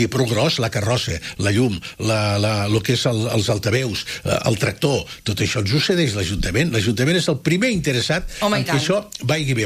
en una entrevista al magazine a l’FM i +